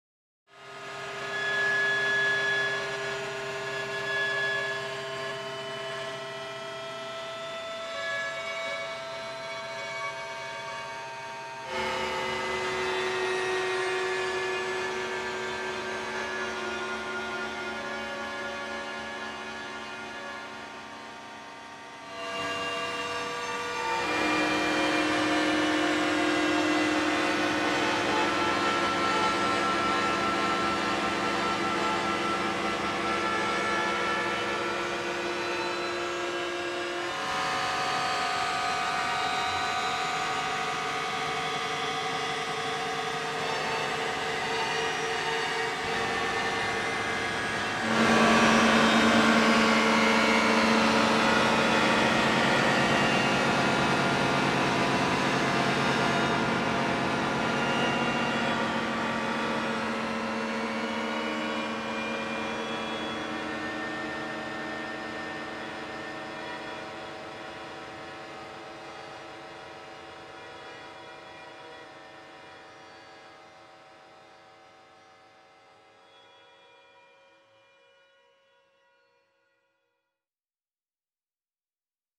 clouds of steel wires …